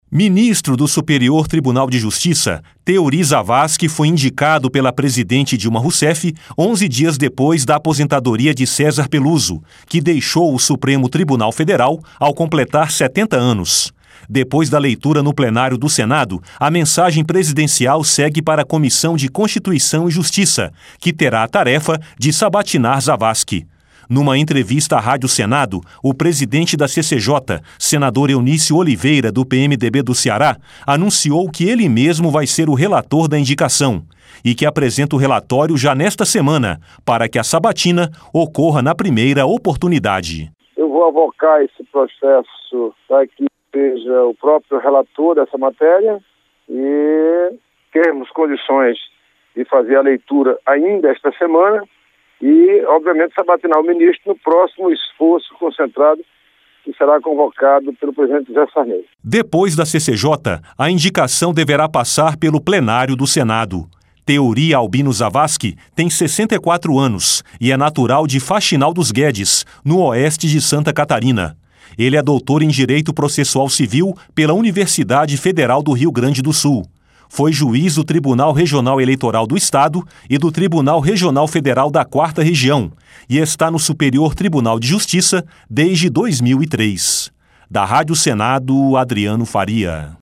Numa entrevista à Rádio Senado, o presidente da CCJ, senador Eunício Oliveira, do PMDB do Ceará, anunciou que ele mesmo vai ser o relator da indicação.